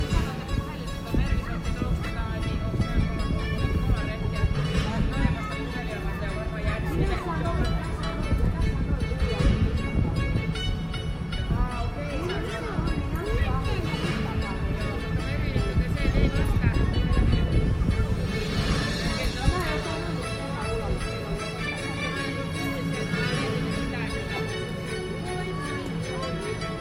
Stockmannin kävelykadunpuoleinen jouluikkuna oli varustettu kolmella kaiuttimella, joista kuului seuraavaa: